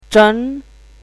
chinese-voice - 汉字语音库
zheng2.mp3